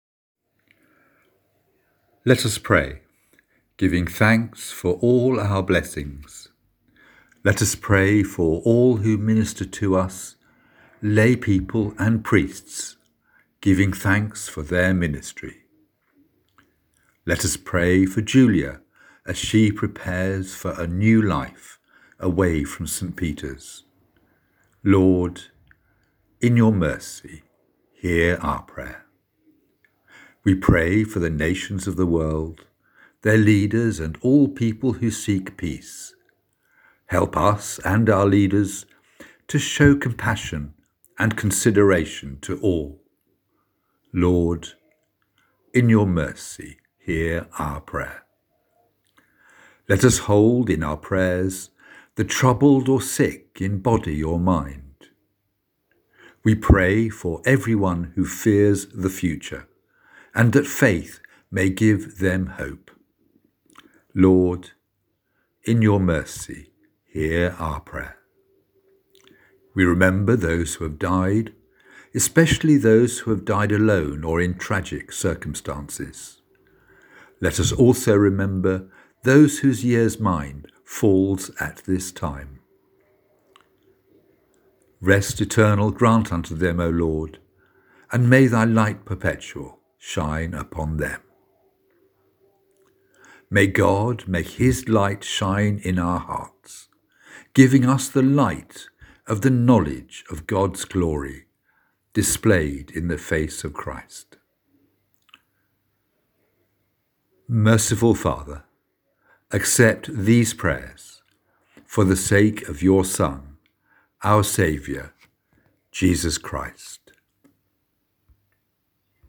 Intercessions